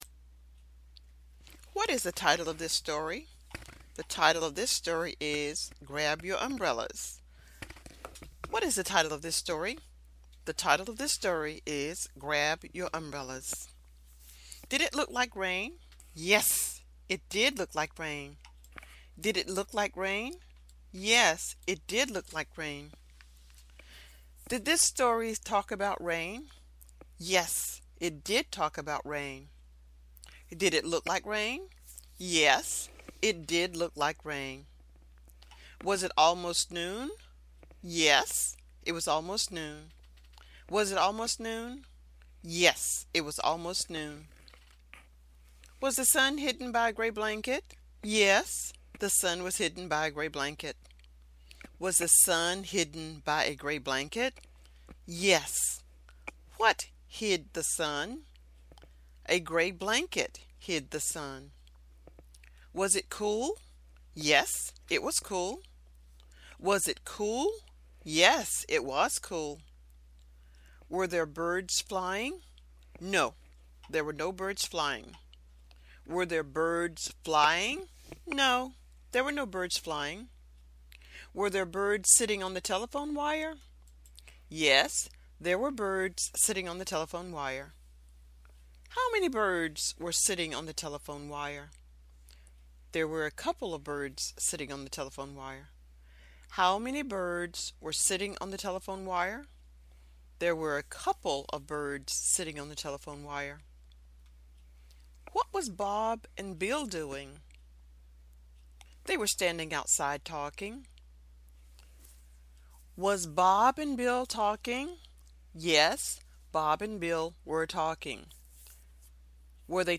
Answer the questions as quickly as you can. The narrator will also give the answers.